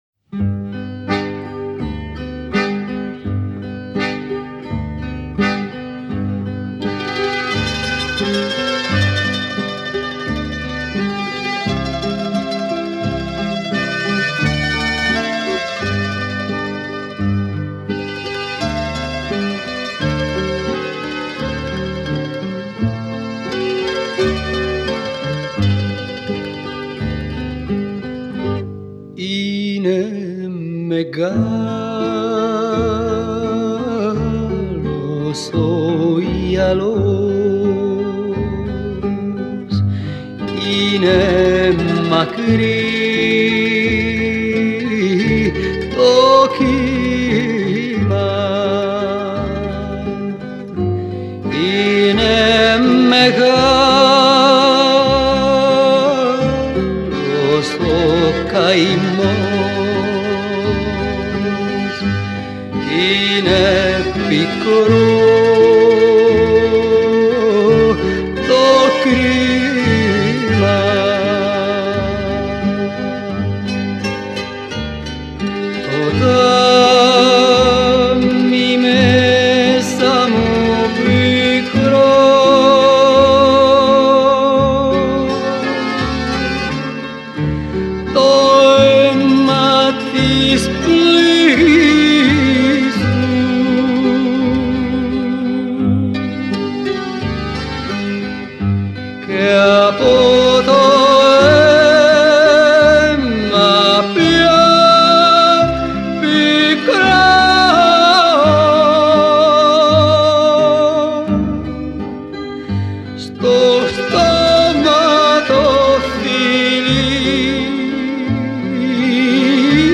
está basada en melodías tradicionales griegas
bella, suave y nostálgica canción de amor